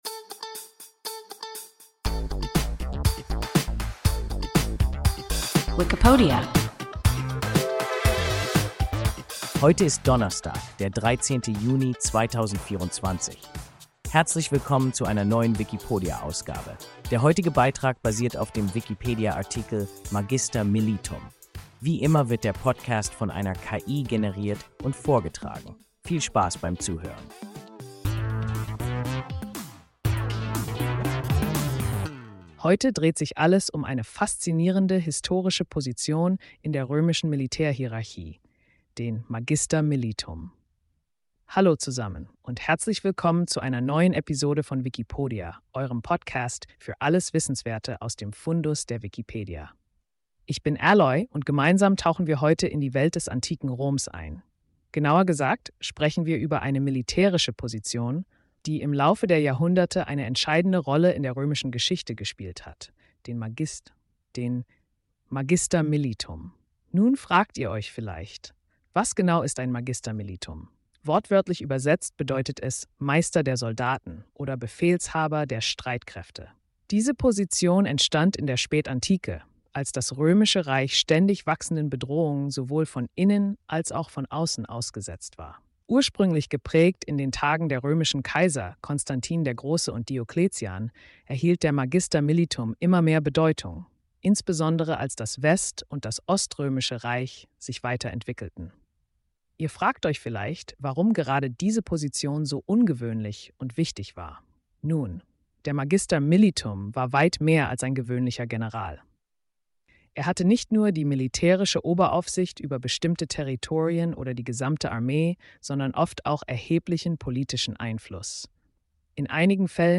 Magister militum – WIKIPODIA – ein KI Podcast